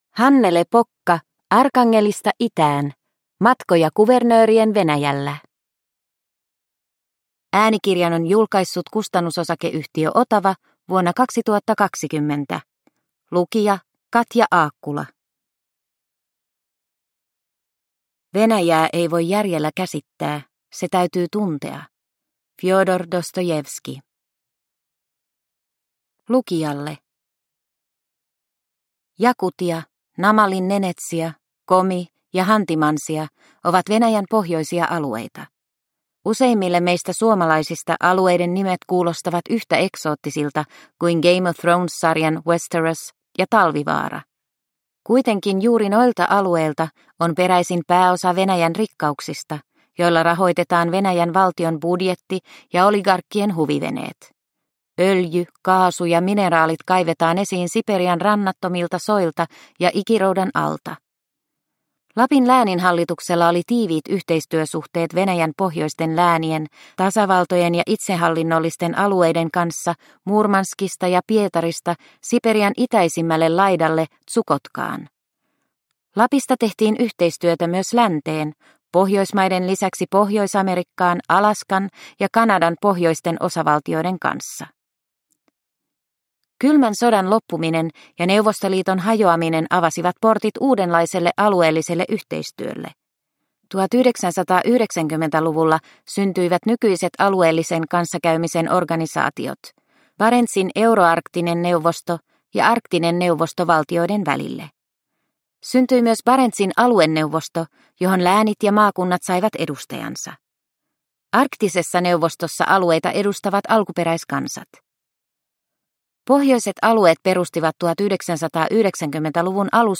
Arkangelista itään – Ljudbok – Laddas ner